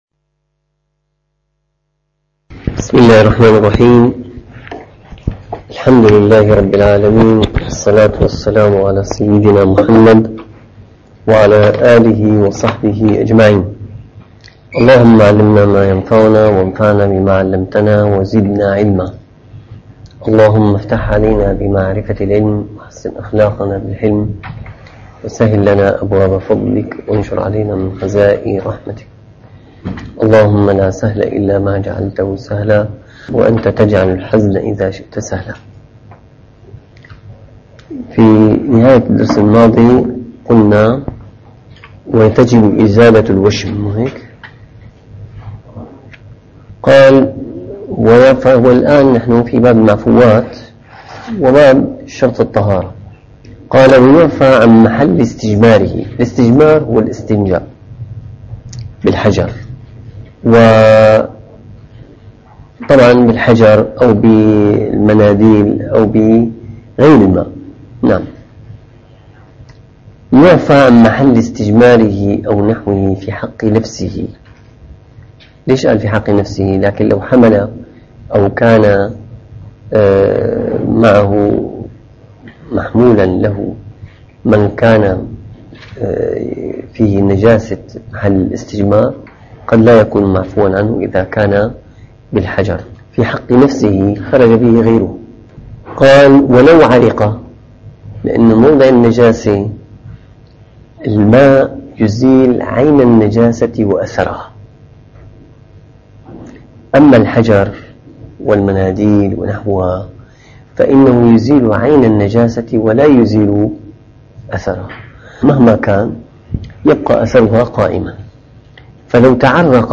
- الدروس العلمية - الفقه الشافعي - المنهاج القويم شرح المقدمة الحضرمية - شروط صحة الصلاة (212-214)